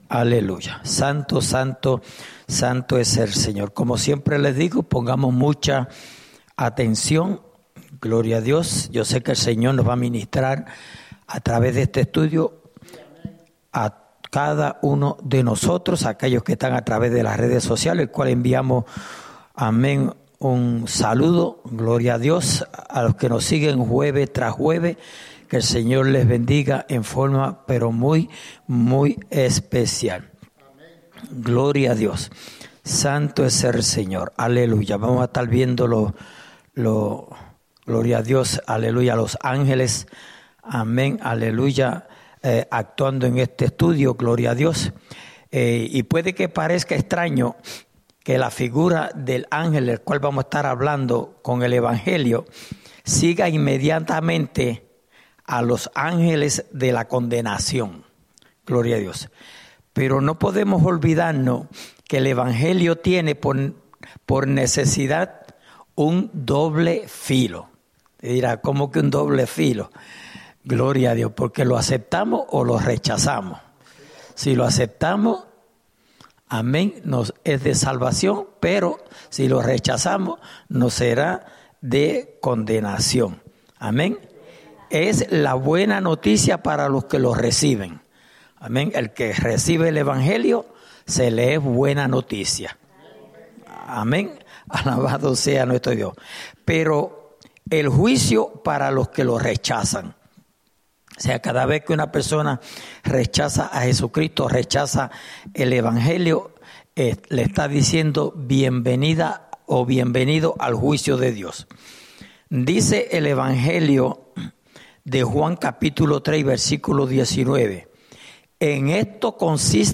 Estudio Bíblico: Libro de Apocalipsis (Parte 27)